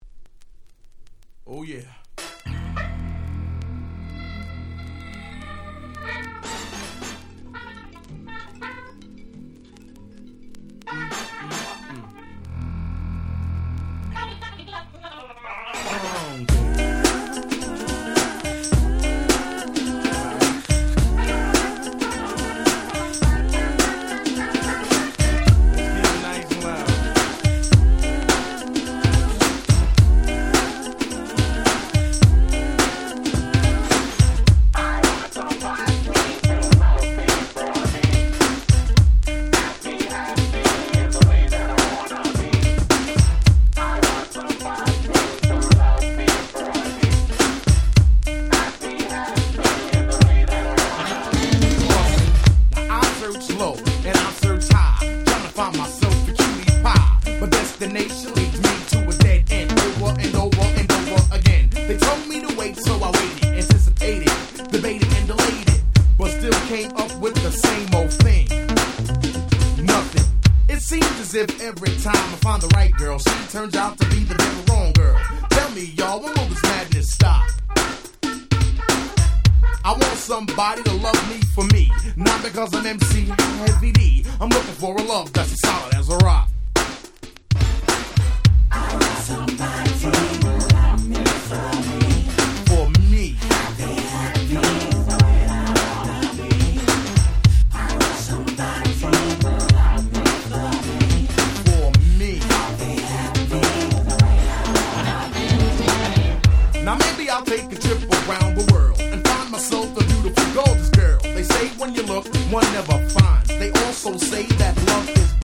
89' Smash Hit Hip Hop / New Jack Swing !!
ニュージャックスウィング感溢れるハネたBeatに軽快なRapが堪りません！！